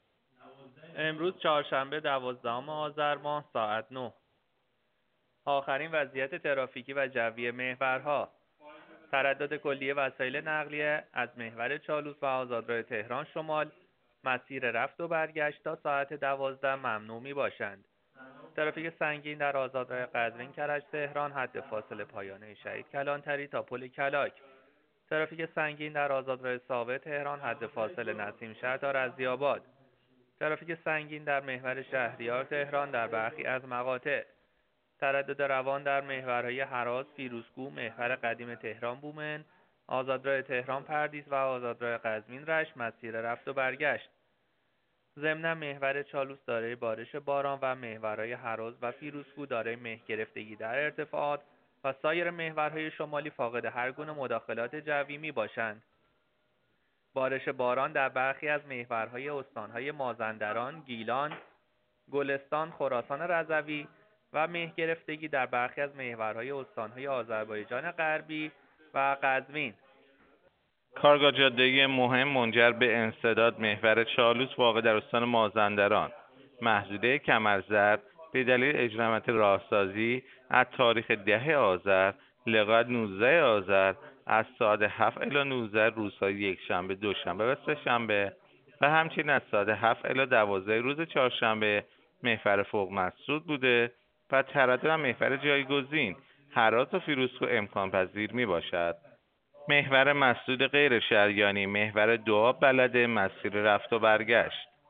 گزارش رادیو اینترنتی از آخرین وضعیت ترافیکی جاده‌ها ساعت ۹ دوازدهم آذر؛